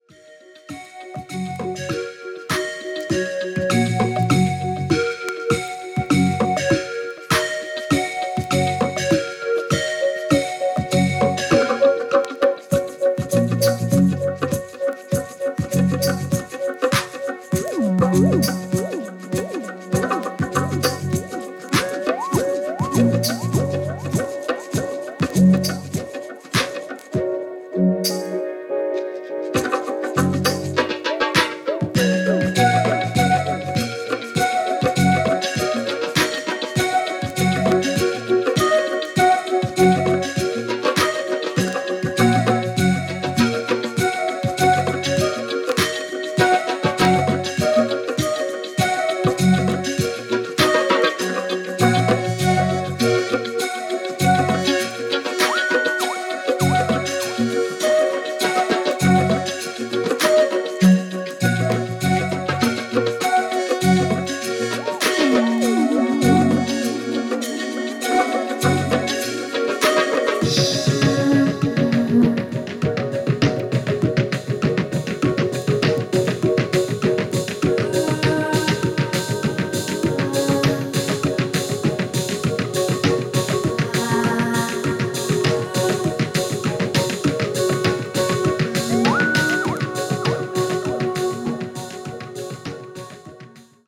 デトロイトの人とは思えないバレアリック感漂う